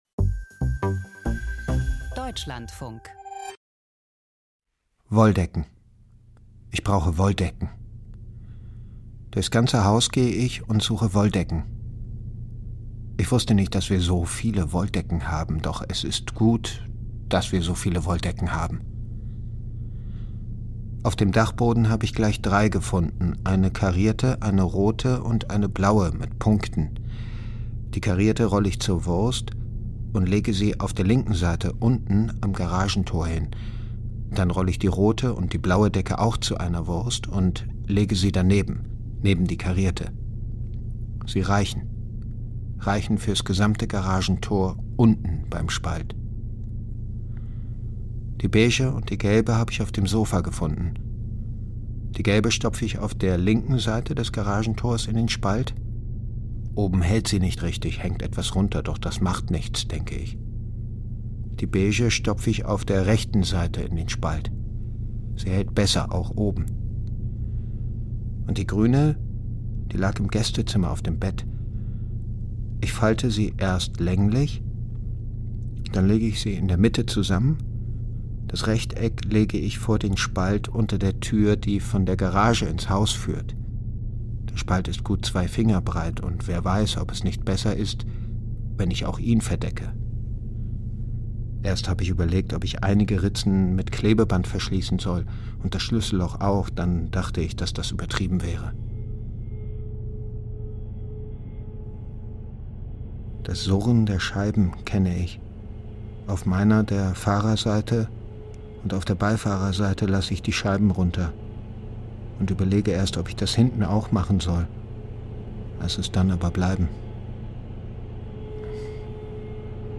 Hörspiel: Heldinnengeschichte im Ganovenmilieu - Die Kobra von Kreuzberg (1/3)
In Berlin will eine junge Ganovin endlich ihren ganz großen Coup landen. Ein Hörspiel mit Female Rap und Beats.